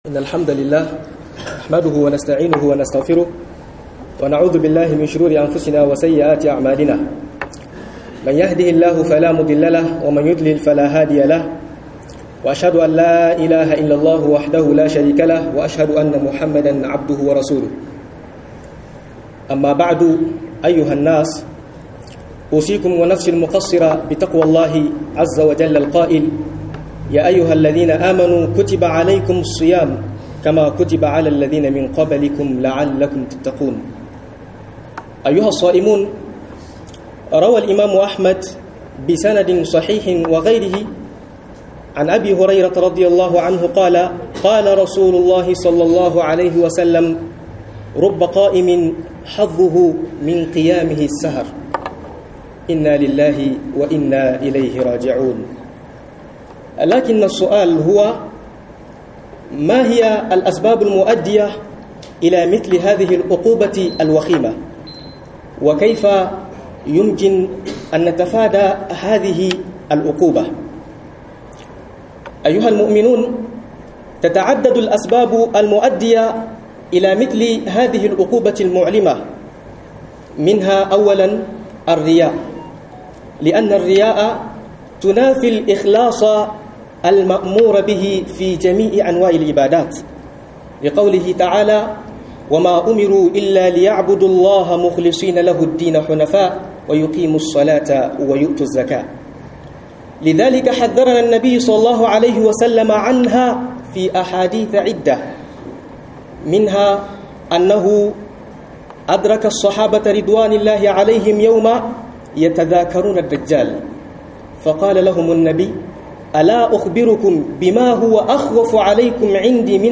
Hattara da yin sallah (Tarawihi) ba lada - MUHADARA